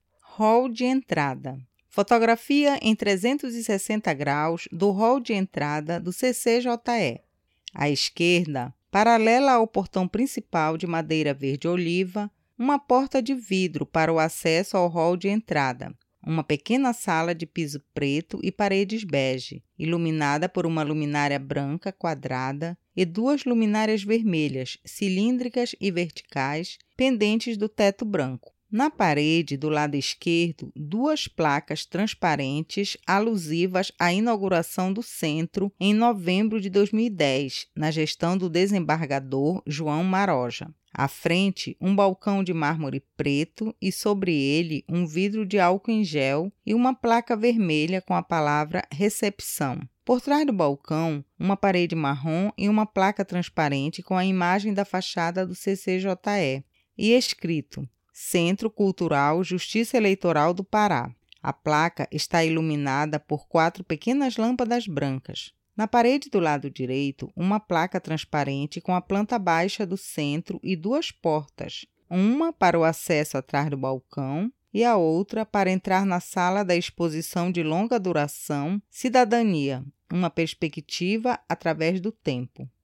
Hall de Entrada audiodescrição